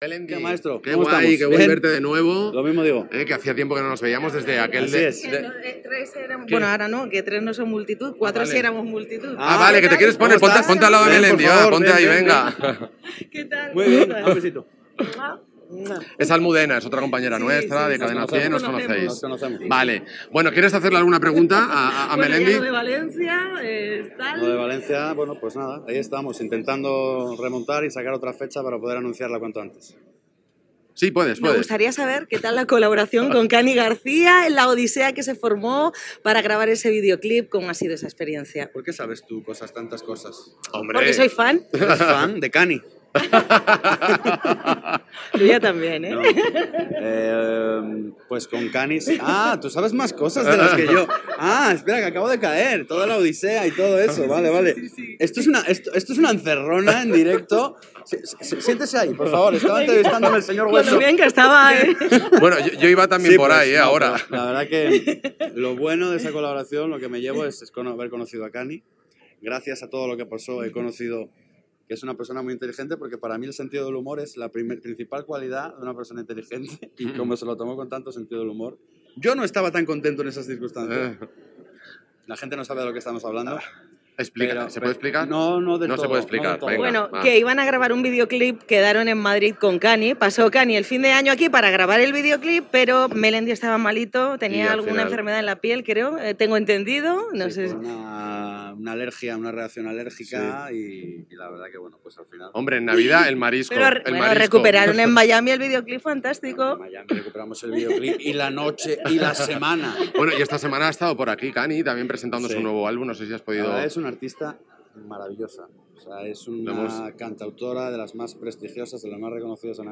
Transmissió del concert "Por ellas" en benefici de l'Asociación Española Contra el Cáncer, des del WiZink Center, de Madrid. Entrevista al cantant Melendi
Musical